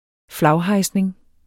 Udtale [ ˈflɑwˌhɑjsneŋ ]